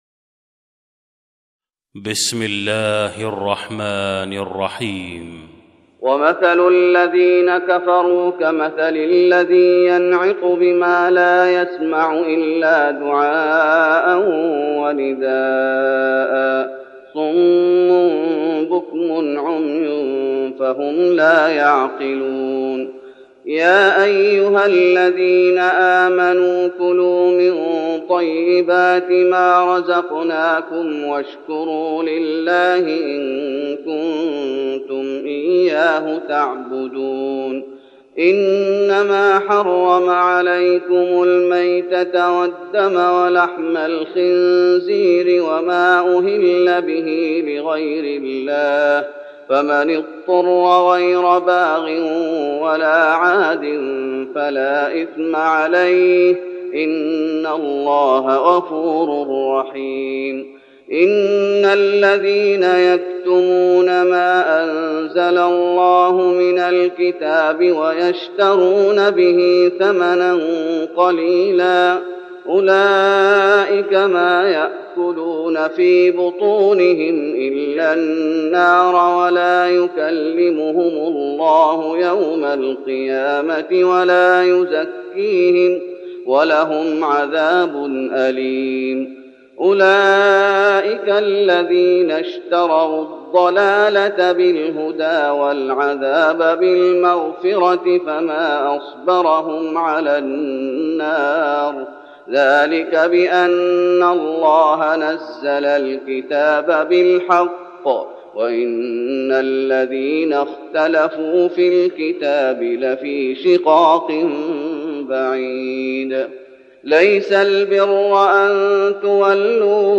تهجد رمضان 1412هـ من سورة البقرة (171-203) Tahajjud Ramadan 1412 H from Surah Al-Baqara > تراويح الشيخ محمد أيوب بالنبوي 1412 🕌 > التراويح - تلاوات الحرمين